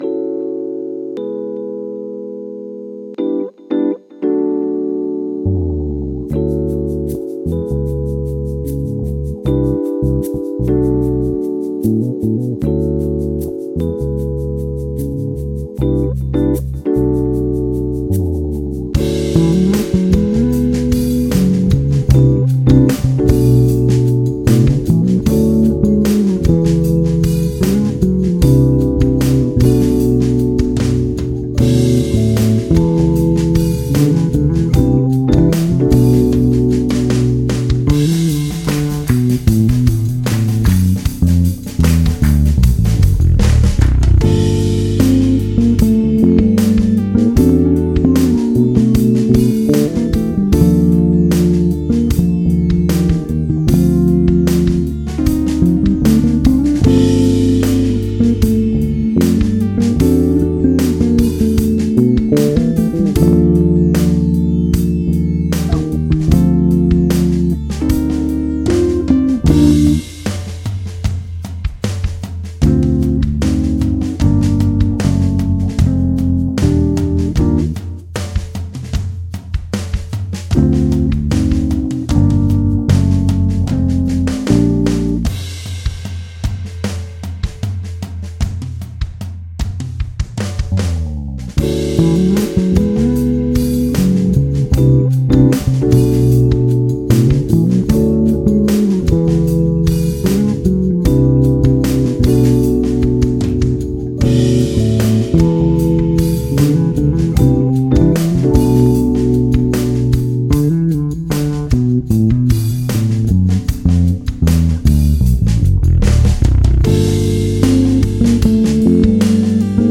In mijn ideale wereld is er muziek met echte instrumenten, funky ritmes en melodieën die blijven hangen.
Muziek van vroeger spreekt me meer aan: energiek, funky en ik word er blij van.
In mijn nummer speel ik twee verschillende baspartijen en de piano, om te laten horen dat de wereld leuker wordt met een beetje meer funk, soul en plezier in de muziek.